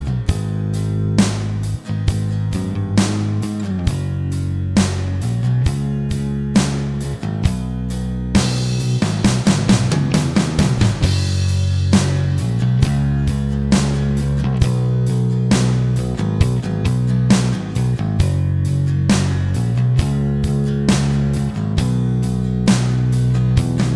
Minus Guitars Rock 5:32 Buy £1.50